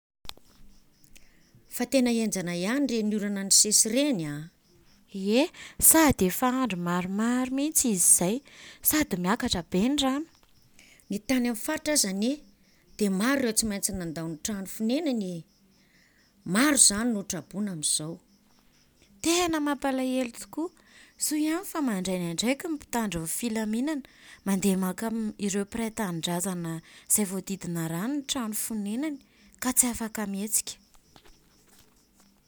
PH: Fa téna énjana iani ‘réni ouran nisési ‘réni a.